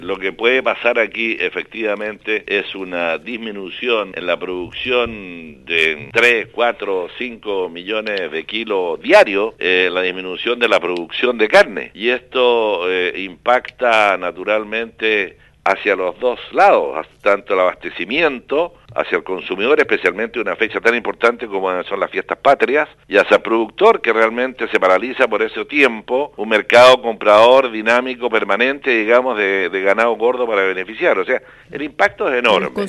En conversación exclusiva con radio Sago